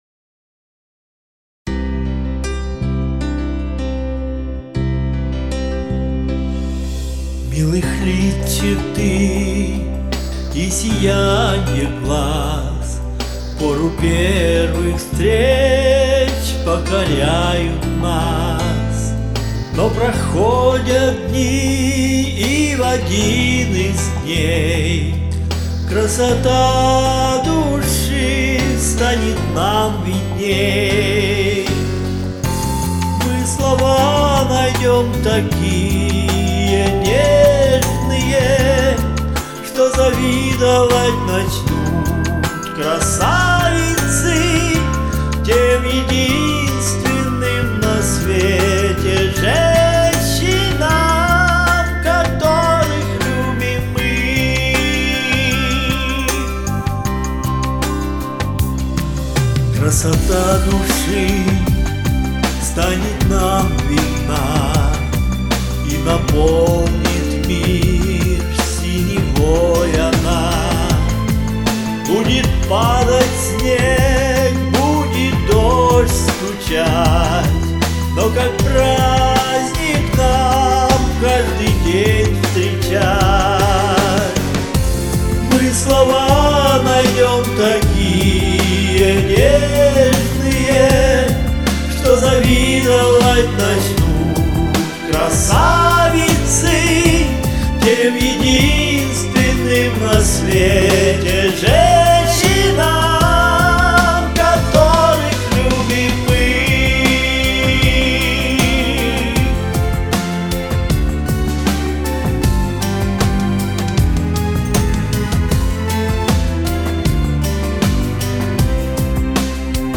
Хорошее такое ретро.